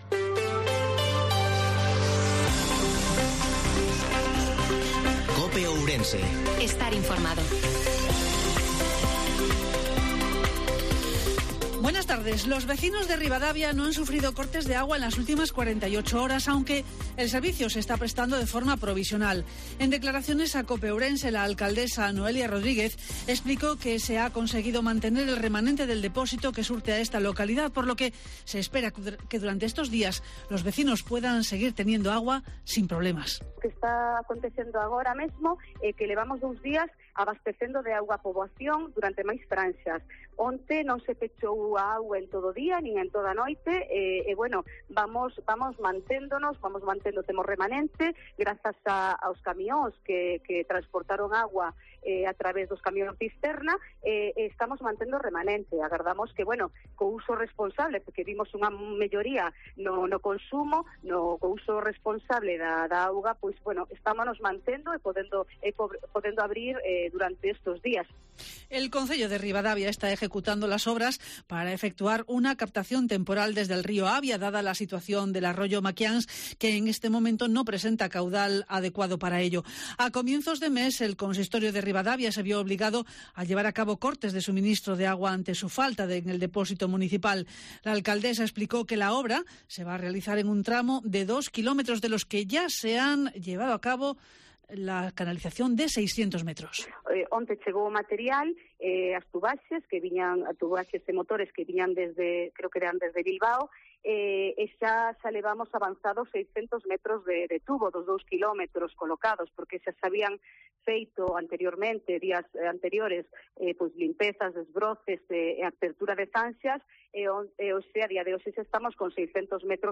INFORMATIVO MEDIODIA COPE OURENSE-19/08/2022